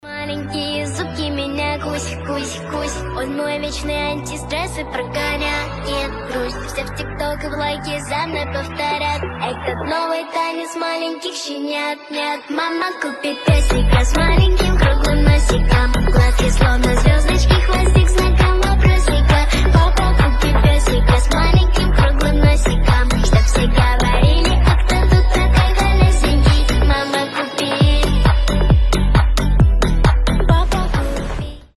• Качество: 320, Stereo
детский голос
детские